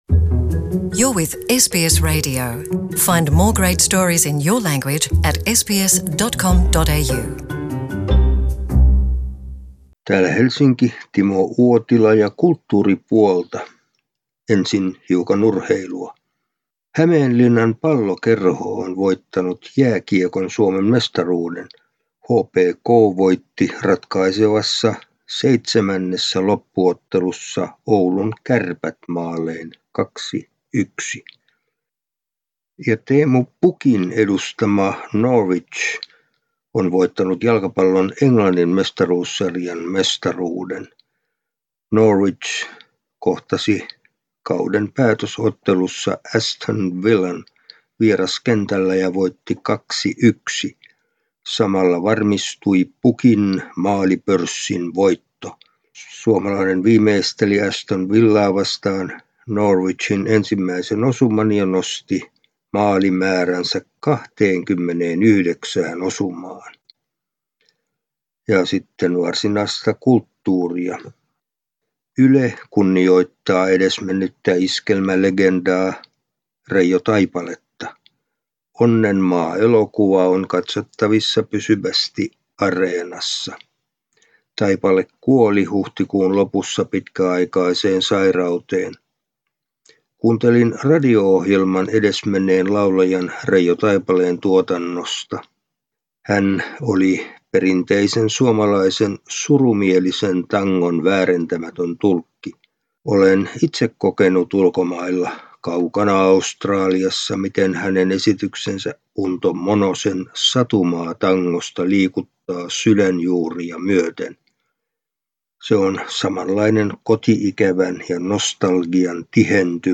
kulttuuri- ja urheiluraportti Suomesta